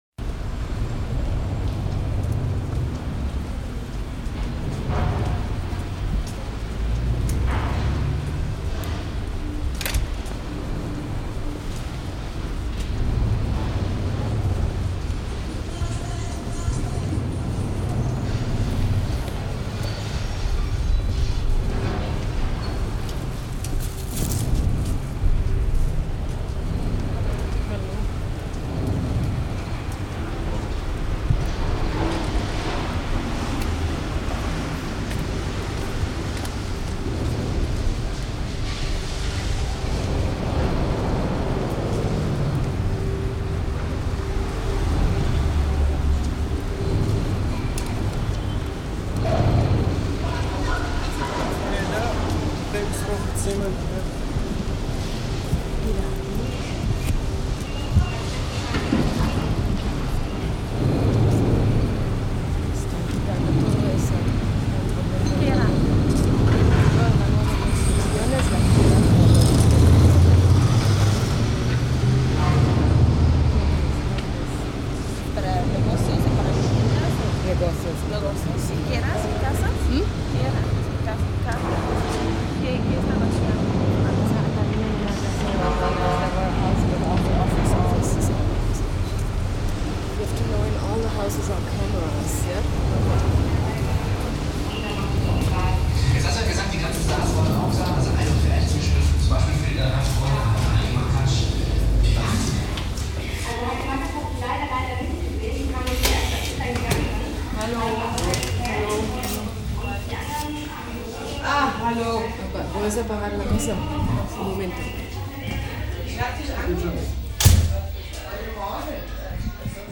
soundscapes, minimal-house, and electro-beats